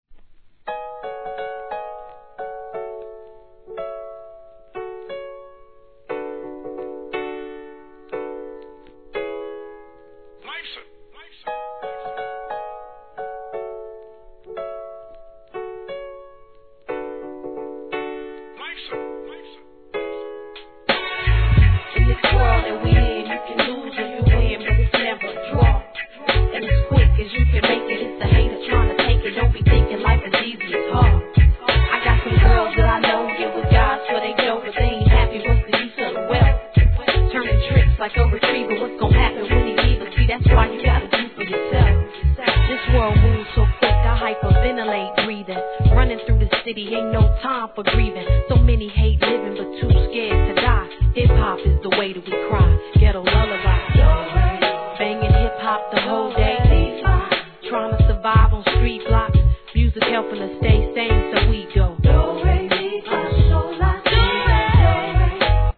HIP HOP/R&B
イントロから哀愁漂うPIANOのメロディーがたまりません♪